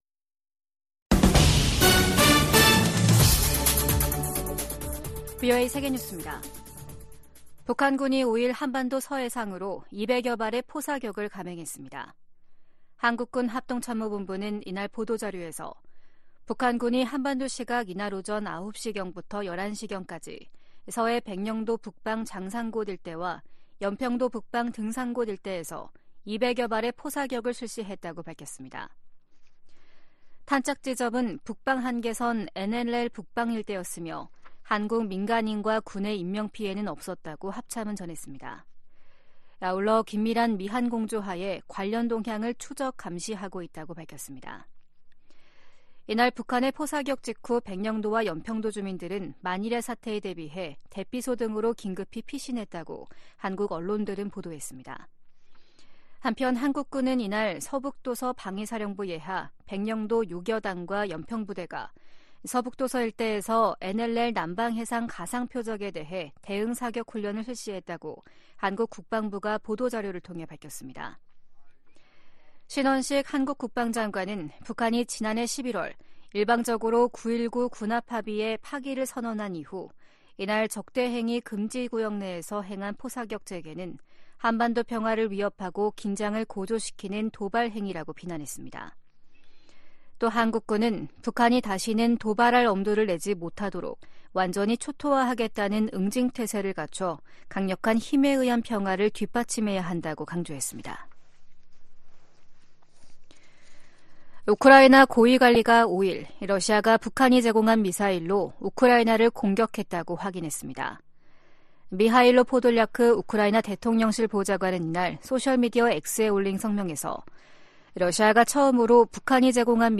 VOA 한국어 아침 뉴스 프로그램 '워싱턴 뉴스 광장' 2024년 1월 6일 방송입니다. 북한군이 5일 오전 9시부터 서북도서지역에서 해안포 200여발을 발사했습니다. 최근 예멘 후티 반군이 이스라엘을 향해 발사한 순항 미사일 파편에서 한글 표기가 발견됐습니다. 최근 러시아가 북한으로부터 탄도미사일을 제공받아 우크라이나 공격에 사용했다고 미국 백악관이 밝혔습니다.